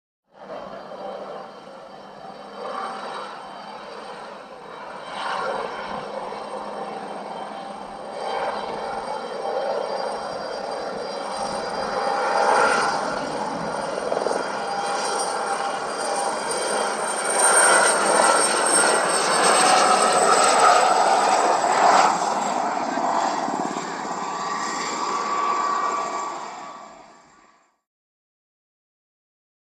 HELICOPTER AEROSPATIALE SA-341 GAZELLE: EXT: Approach, by. Medium speed.